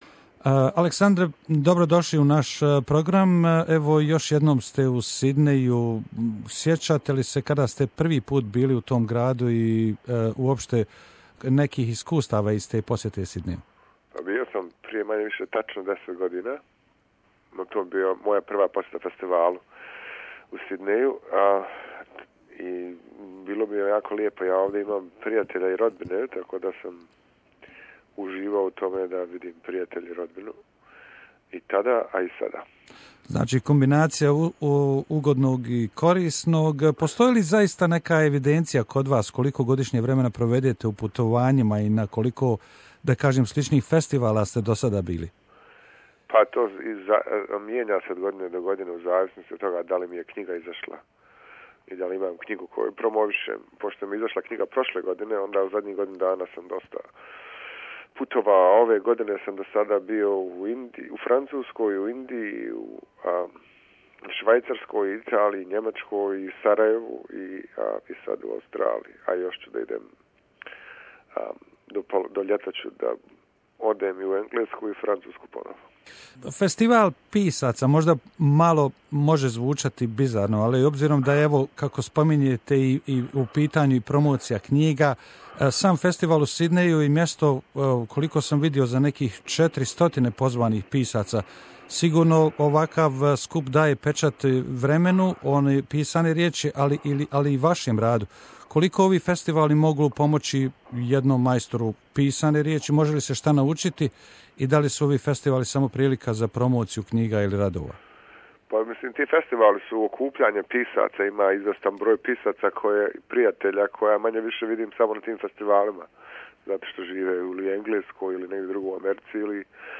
Interivew with Aleksandar Hemon, Bosnian born author today living in USA.